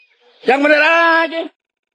Suara Yang Bener Aja Meme
Kategori: Suara viral
suara-yang-bener-aja-meme-id-www_tiengdong_com.mp3